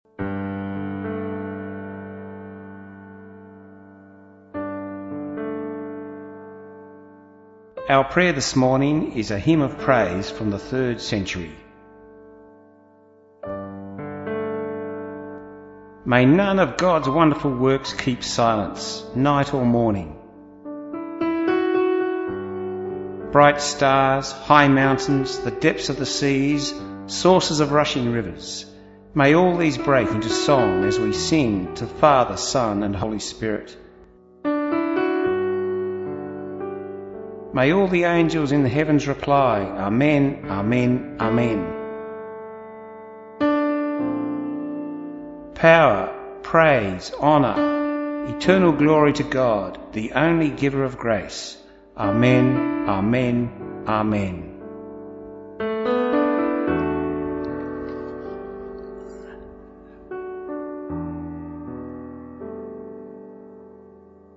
Our Songs of Hope prayer for Sunday 11Dec16 was a 3rd century Christian hymn of praise